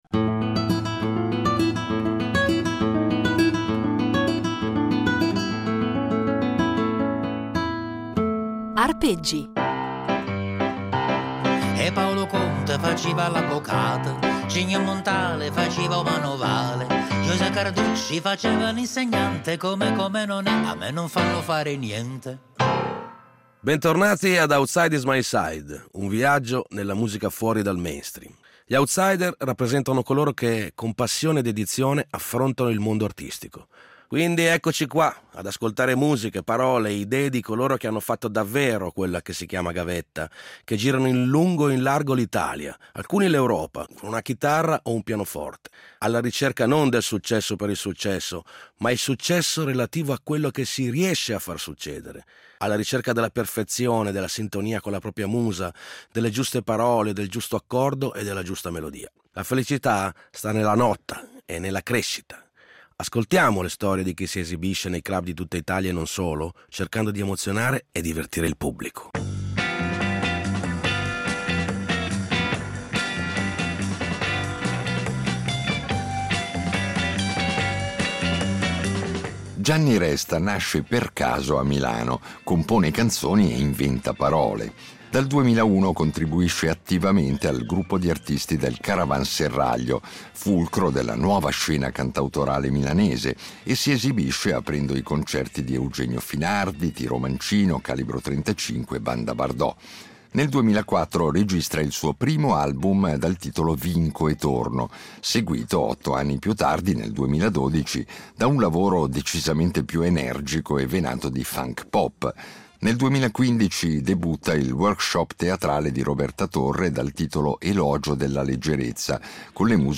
Sì, divertire, perché la musica d’autore è anche molto divertente e in queste 10 puntate ce ne accorgeremo, grazie alla disponibilità di dieci outsider o presunti tali, che ci offriranno dal vivo anche un assaggio della loro arte.